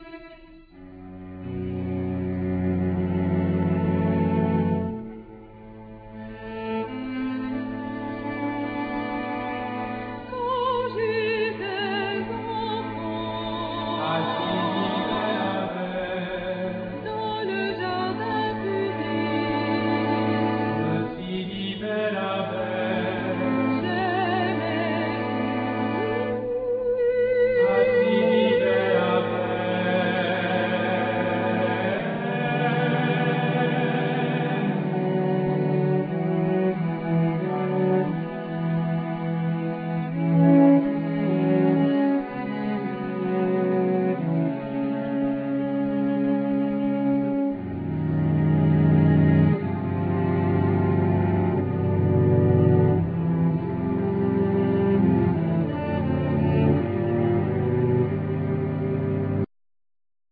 Violin
Viola
Cello
Double bass
Vocals
Flute
Clarinet
Hautbois
Basson
Alt sax
Tennor and Soprano sax
Trombone
Soubassophone
Guitars
Synthsizer
Piano
Marimba,Vibraphone
Percussions